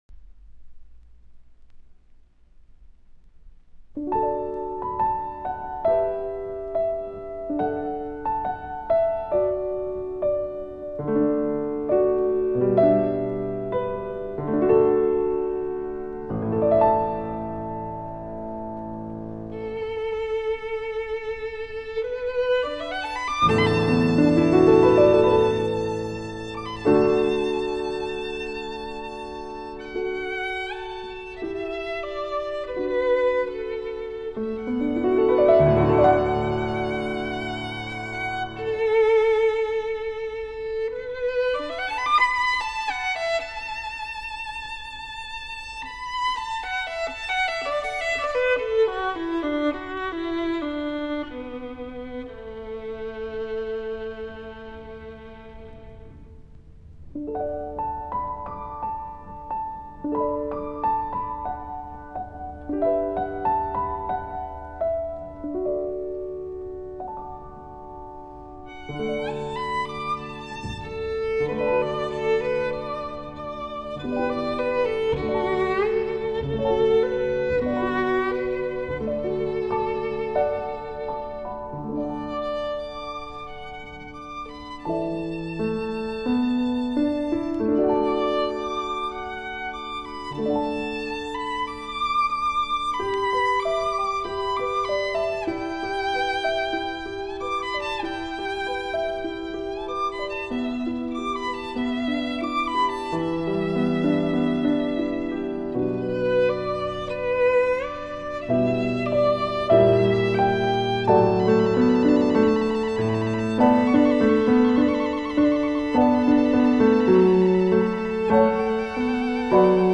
黑膠轉WAV轉320K/mp3